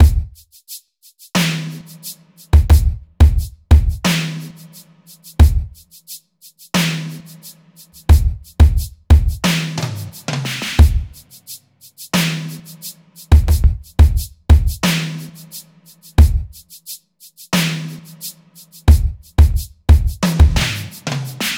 03 drums A.wav